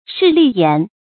势利眼 shì lì yǎn
势利眼发音